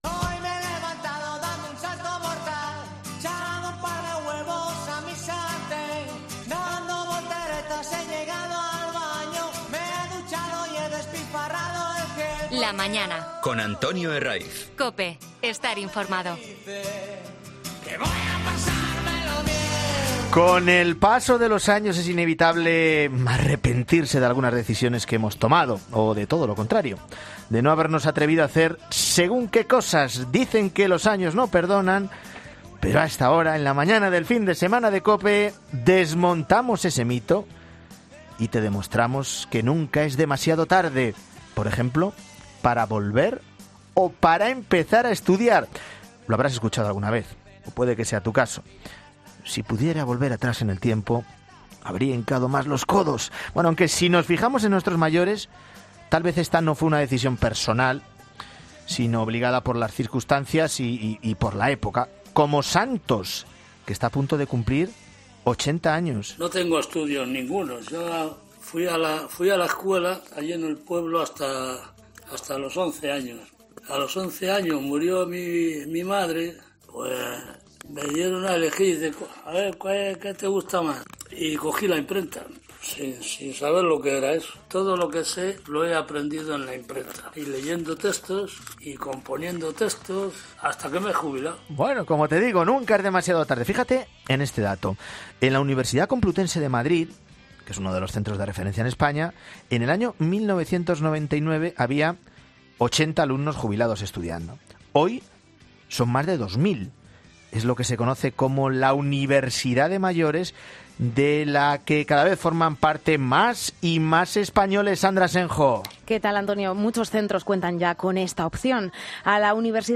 El número de personas mayores de 65 años que estudian un grado universitario se ha multiplicado por 4 en la última década y en 'La Mañana Fin de Semana' hablamos con ellos